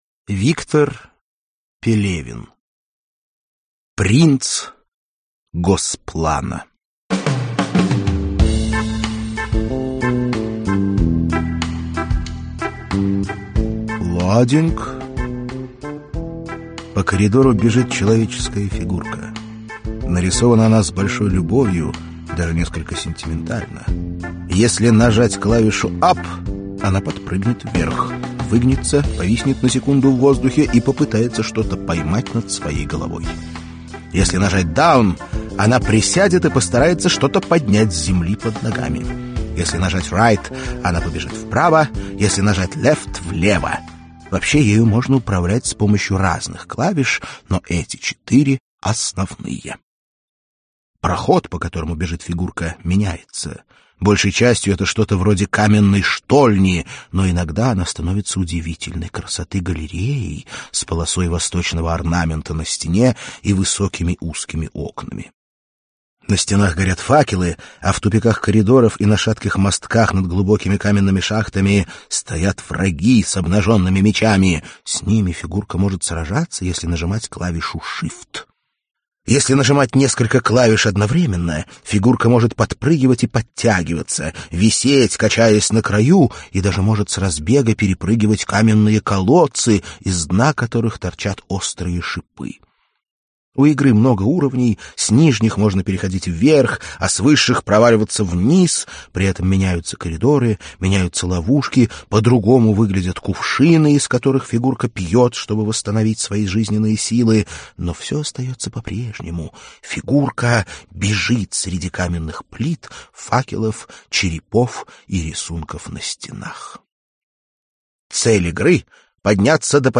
Аудиокнига Принц Госплана | Библиотека аудиокниг